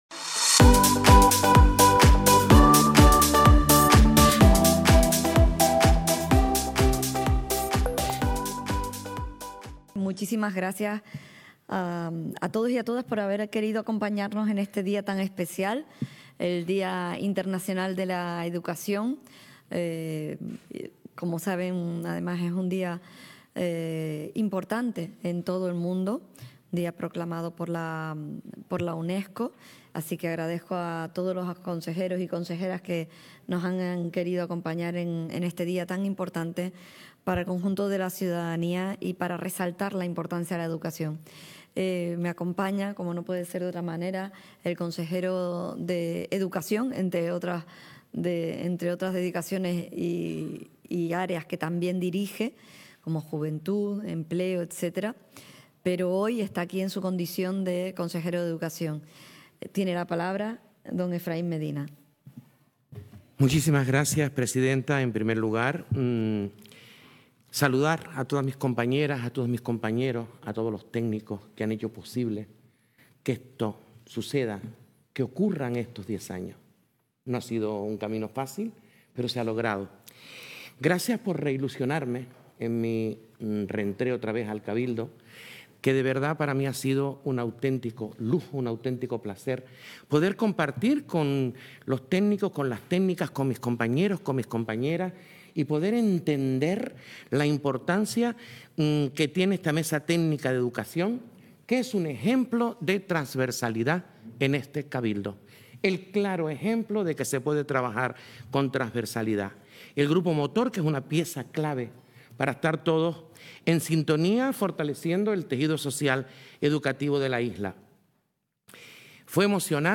El Salón Noble del Cabildo de Tenerife acogió hoy (viernes) el acto de conmemoración del Día Internacional de la Educación, en el que se destacó el décimo aniversario del programa Cabildo Educa.